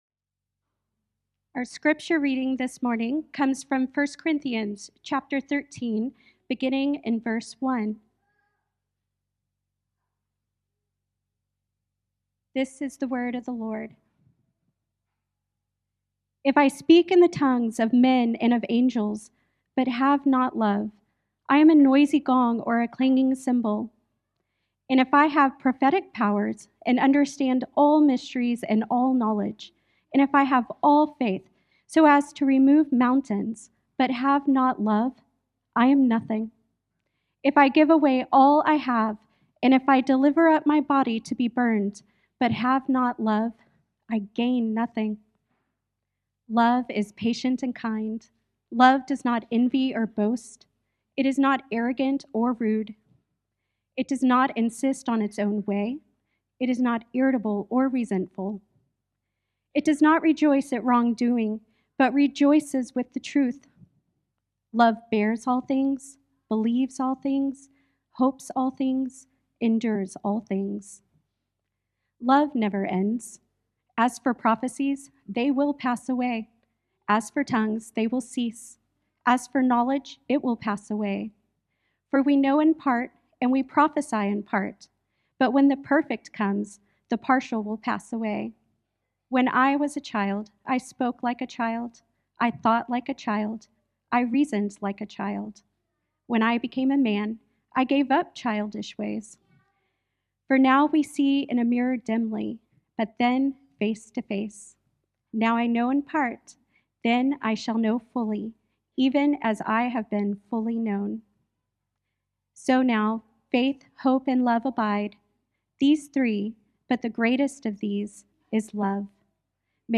January 4 Sermon.m4a